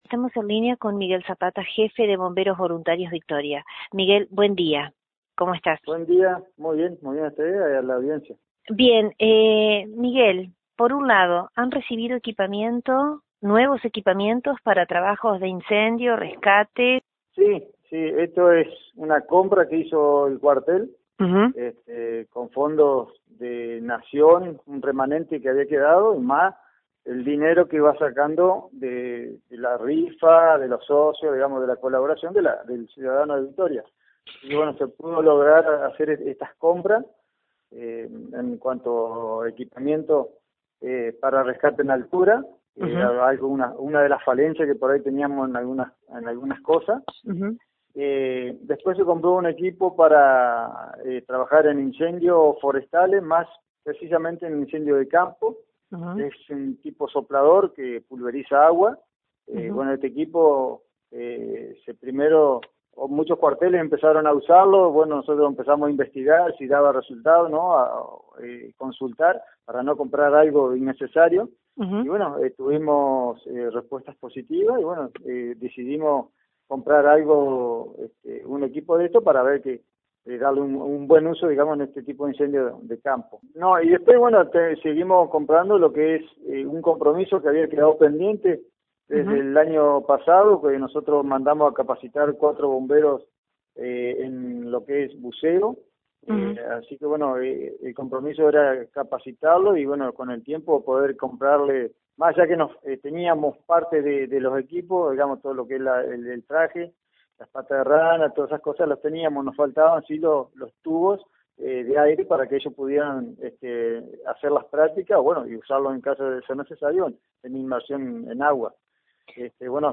En diálogo con LT39 NOTICIAS